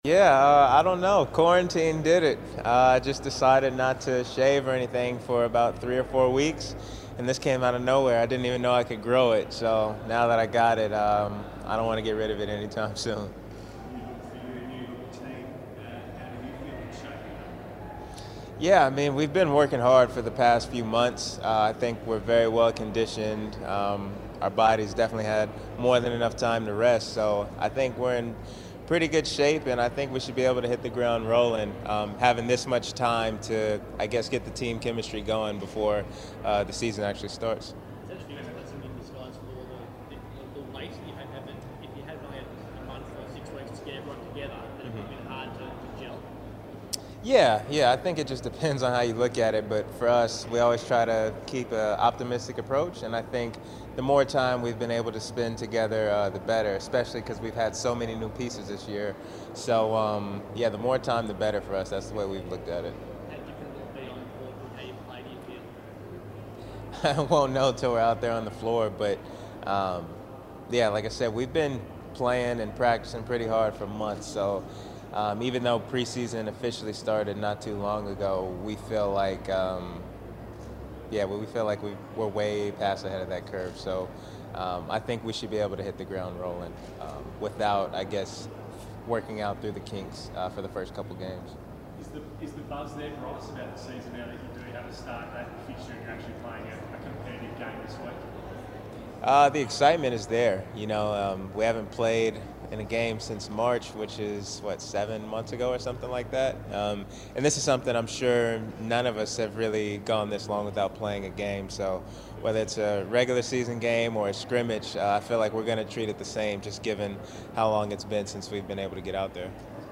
Bryce Cotton Press Conference - 10 December 2020